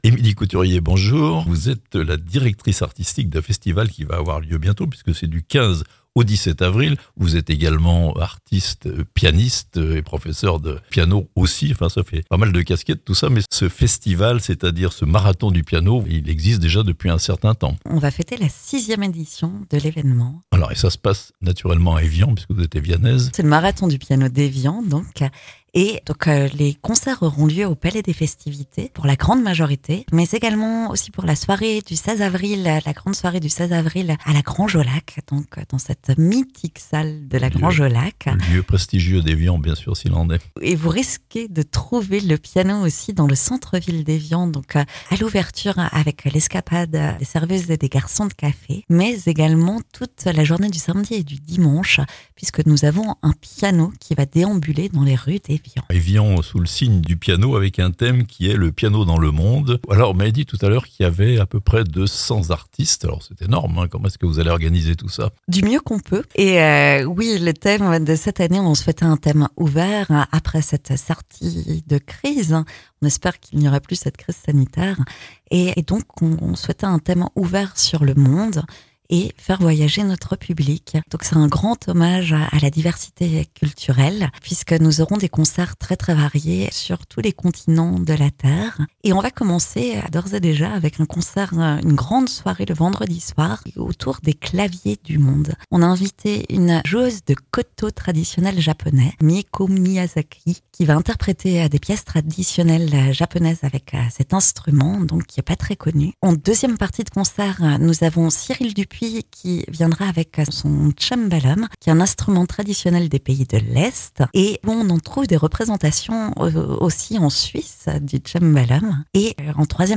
Le piano fera son marathon à Evian (interview)